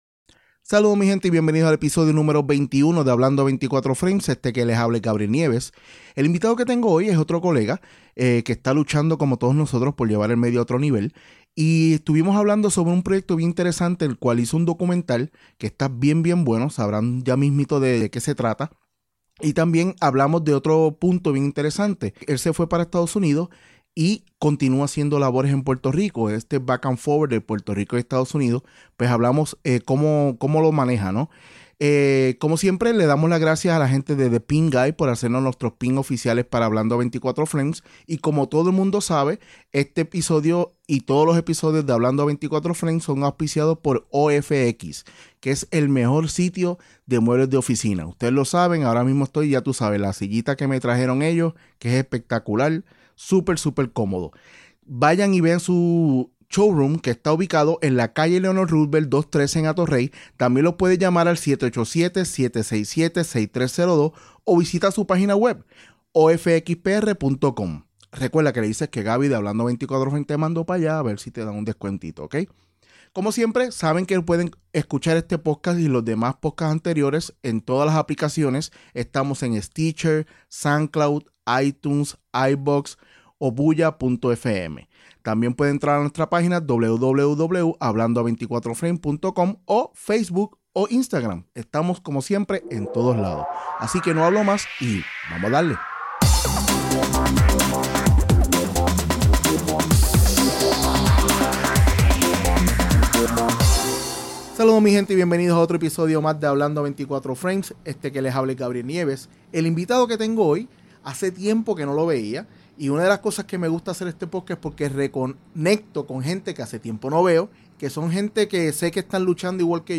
En el episodio #21 hablo con un colega que esta trabajando duro por hacer proyectos de calidad y tiene un documental el cual promueve el movimiento de la agricultura urbana.